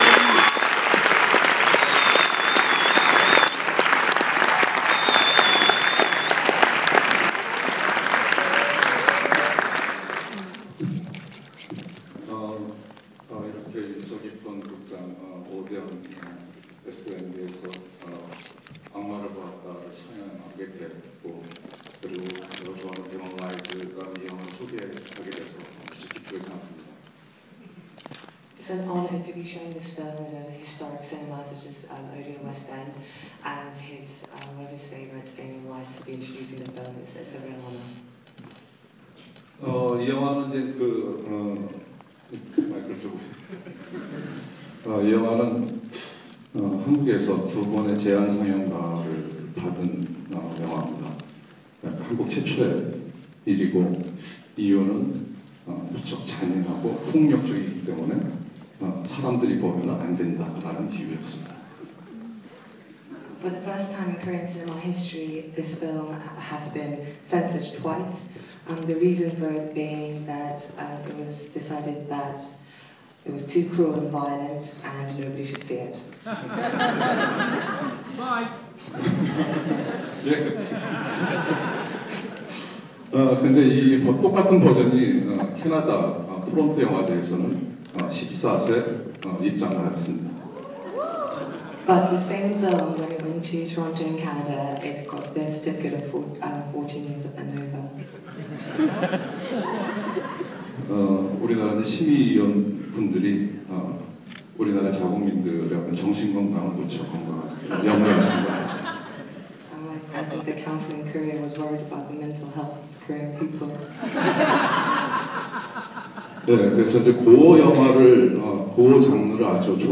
Director Ji-woon Kim introduces I Saw The Devil